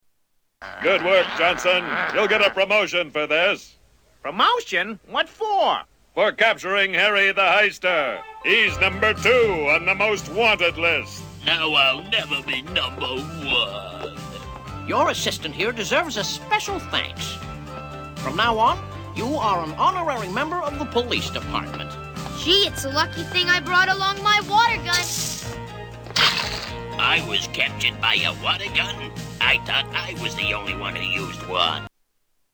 Water gun
Tags: Television Dennis the Menace Cartoon clips Dennis the Menace Cartoons Classic Cartoon